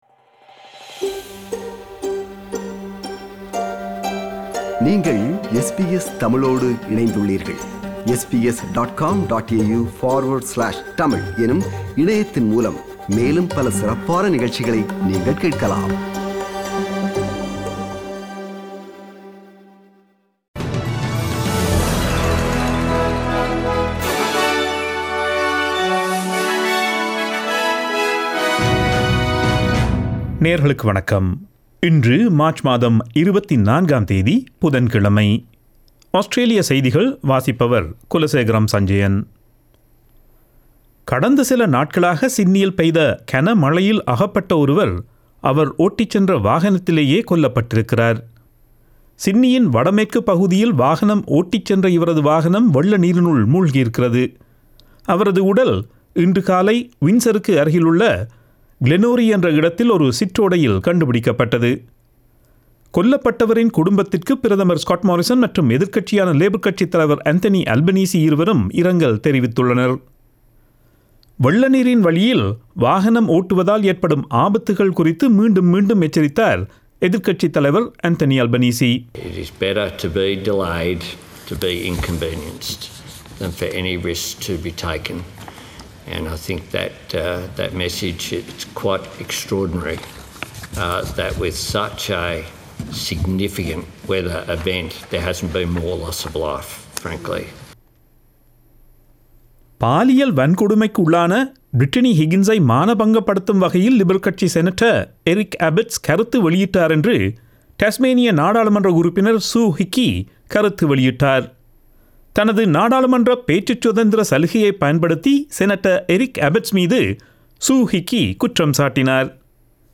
Australian news bulletin for Wednesday 24 March 2021.